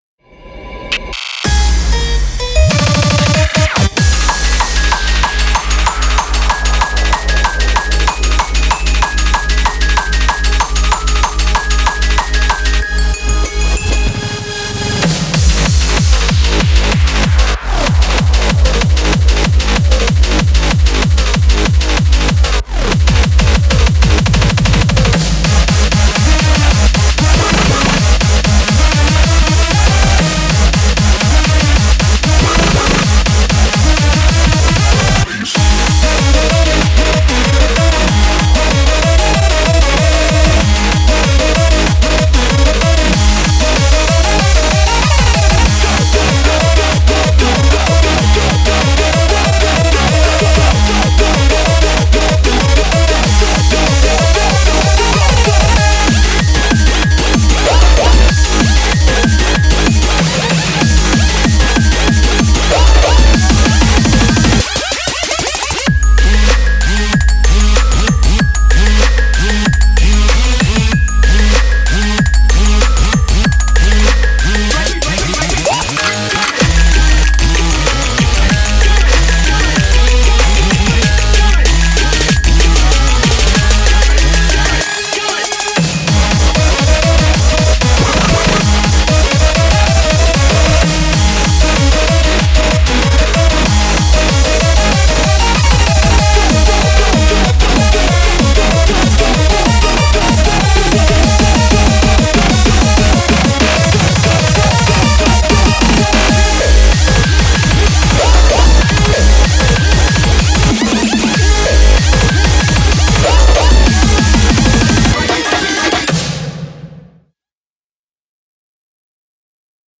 BPM95-190
Audio QualityPerfect (High Quality)